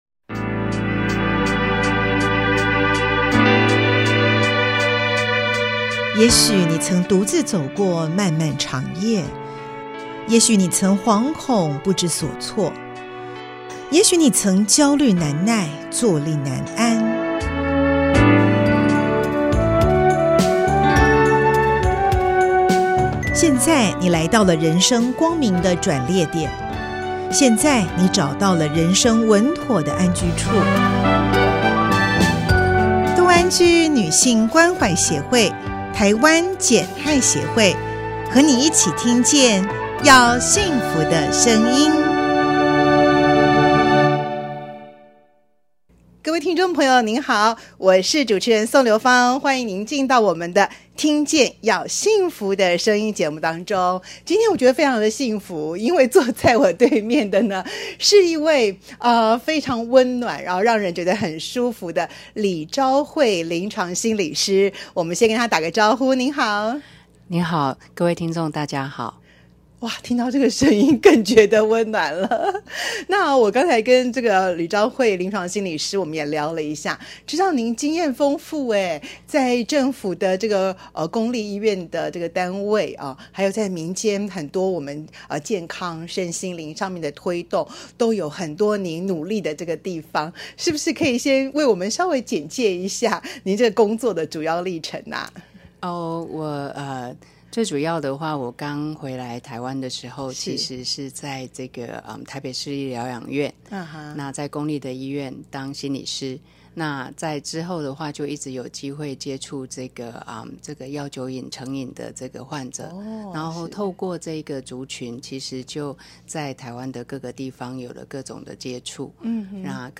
本節目採訪報導此相關領域之學者、專家，深入探討毒品防制之重要及更生人安置之必要，以共維護社會安全與幸福和諧。